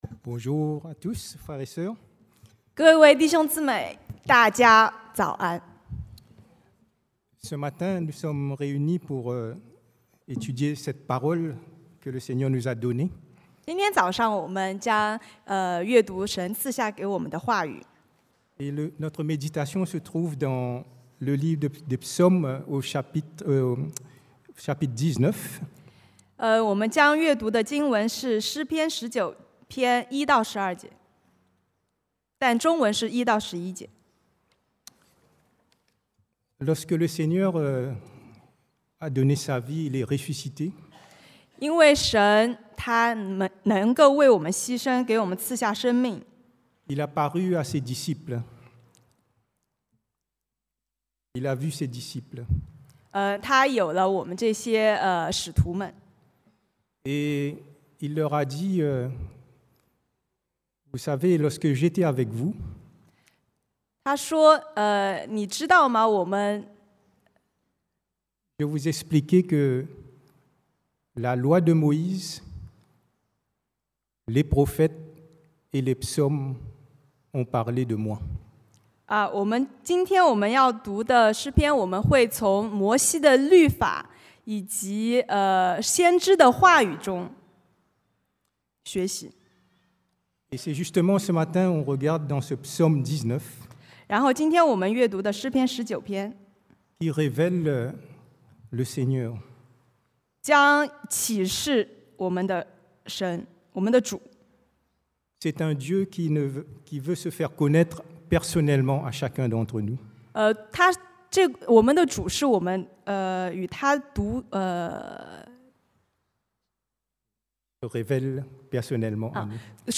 Predication du dimanche